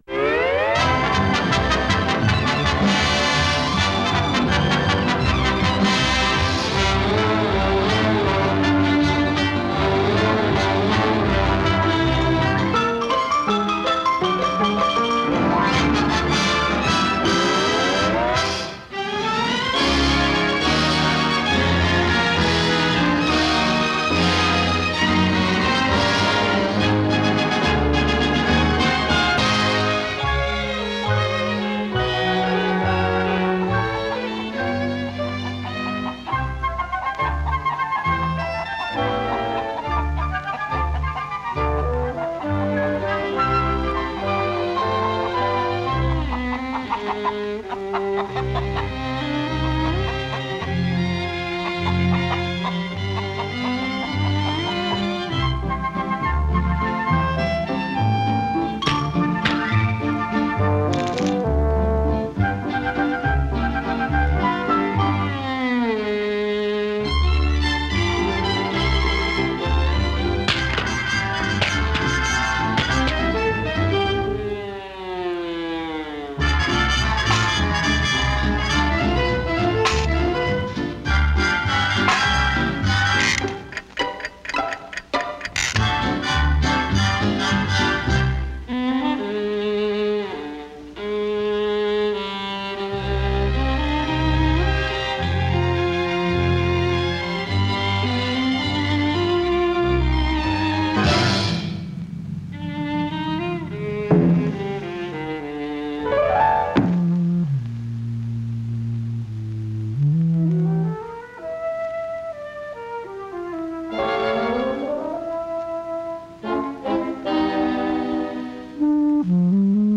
gorgoglio valzereggiante